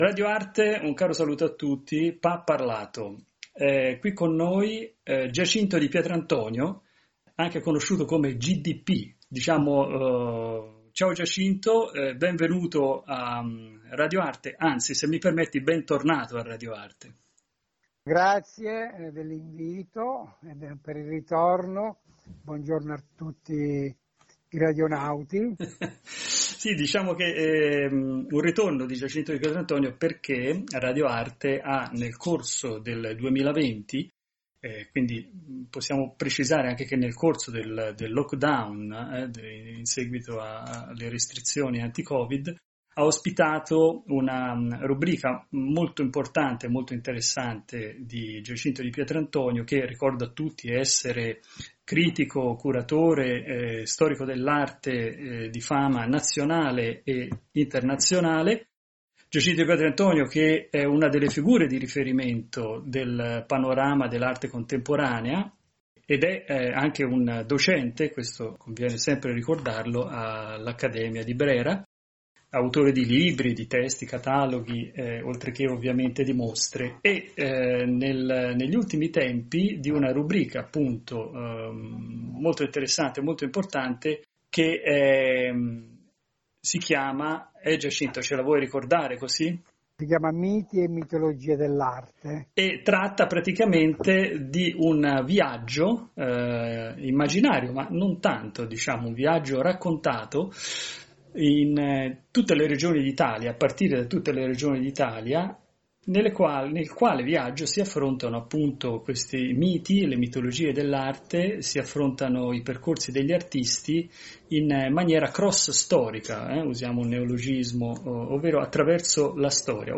Pa-Parlato: Periodic interviews with the protagonists of the art world on topical themes.